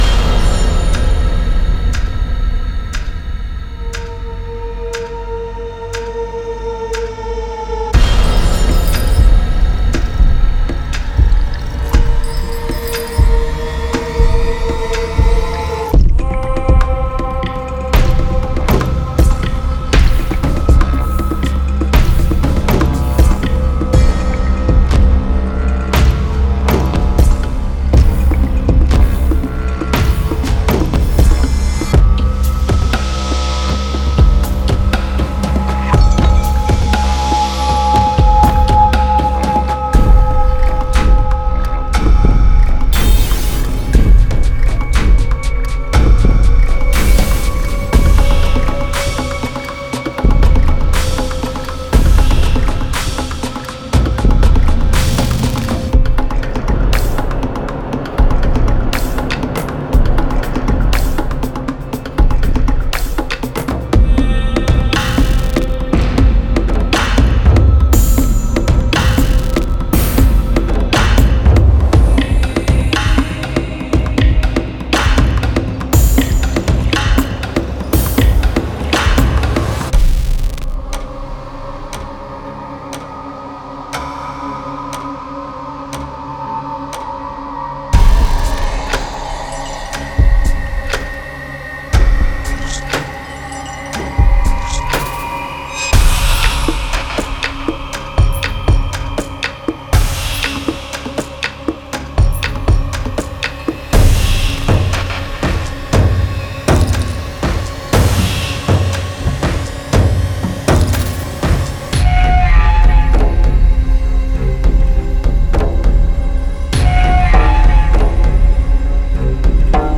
Genre:Cinematic
デモサウンドはコチラ↓
268 Drum Hits
265 Drum Loops